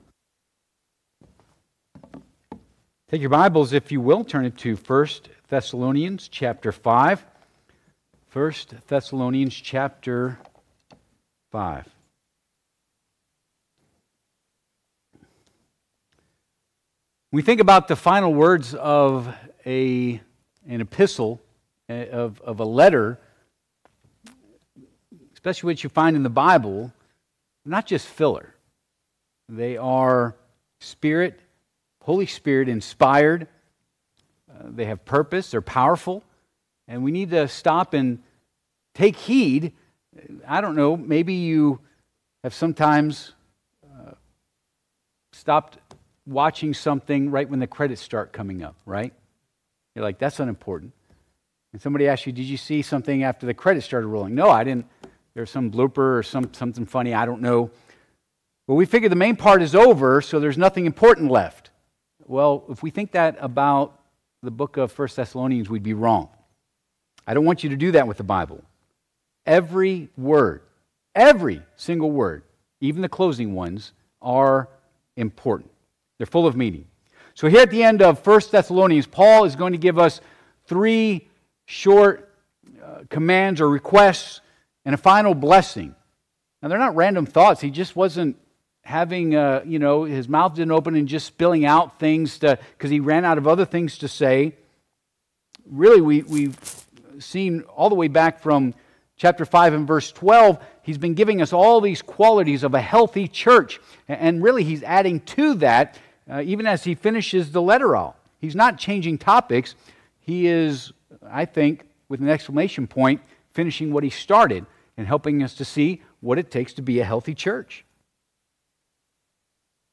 Passage: I Thess. 5:25-28 Service Type: Midweek Service